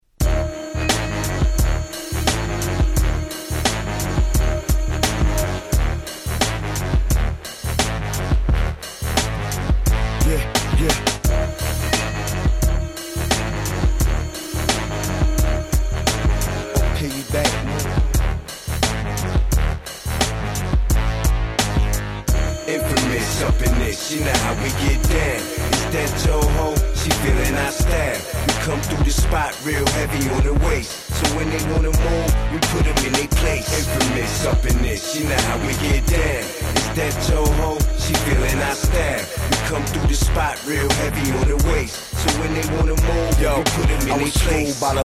06' Smash Hit Hip Hop !!